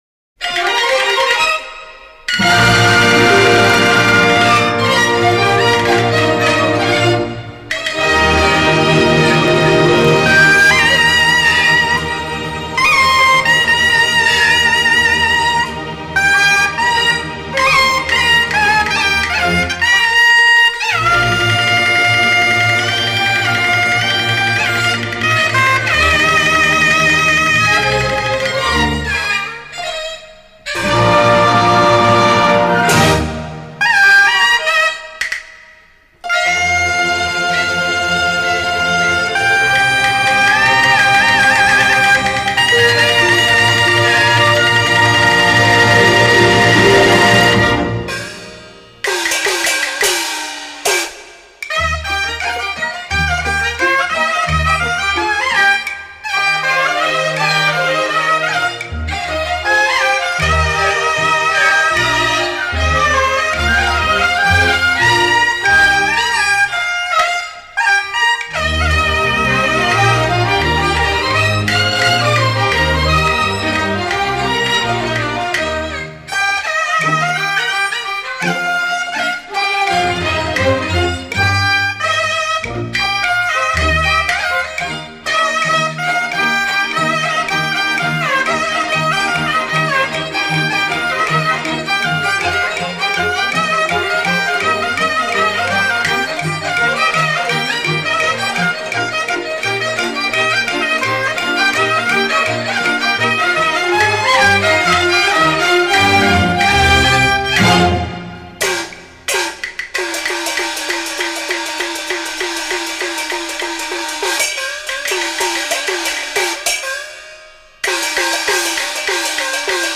音地點：中國·上海